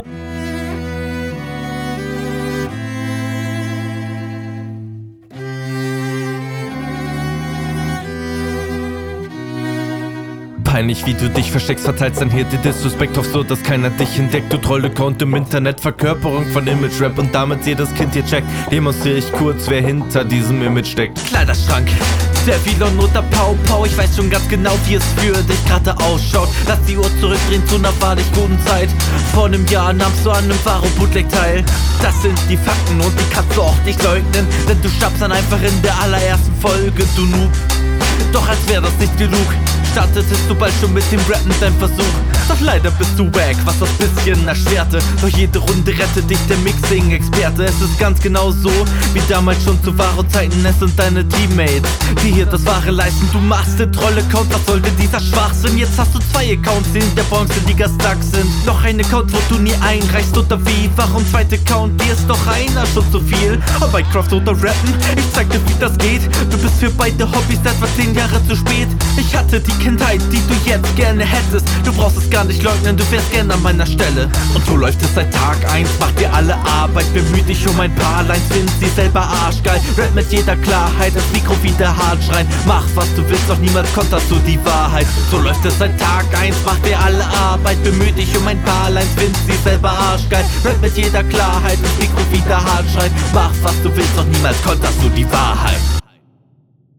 Du kommst auf dem Beat sehr gut.